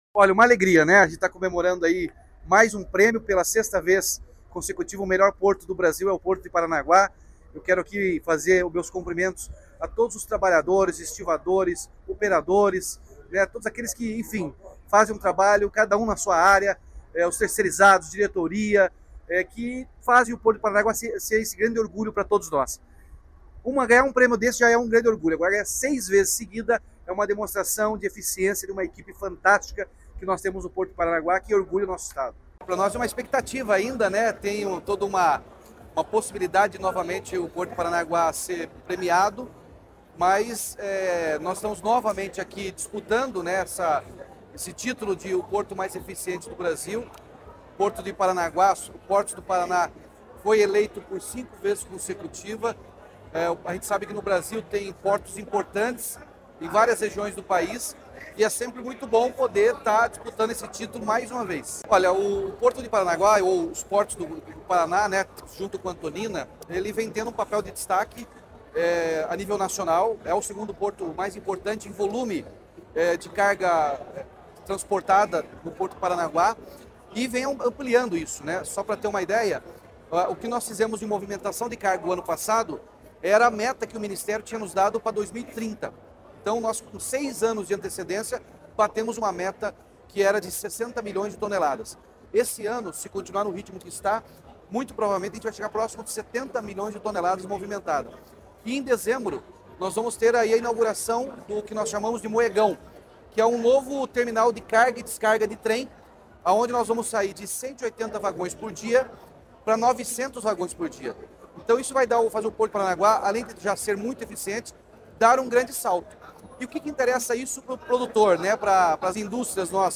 Sonora do governador Ratinho Junior sobre a Portos do Paraná ser hexacampeã do principal prêmio de gestão portuária do Brasil